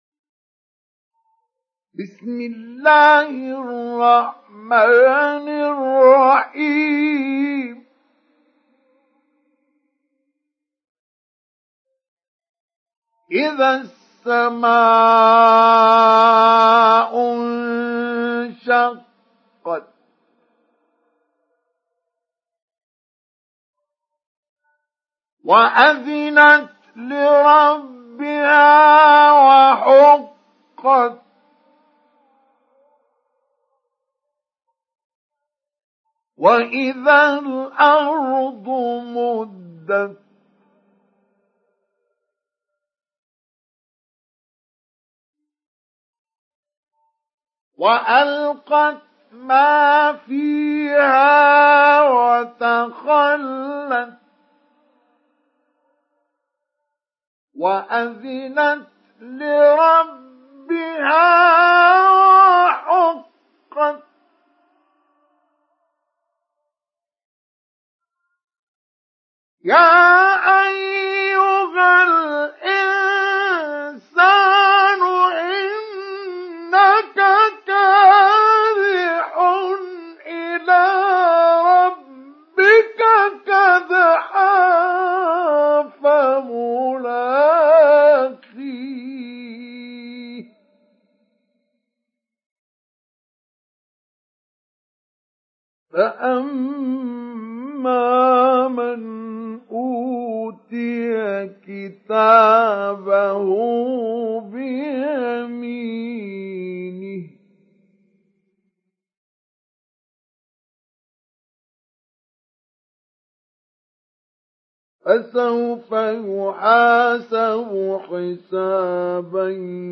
سُورَةُ الانشِقَاقِ بصوت الشيخ مصطفى اسماعيل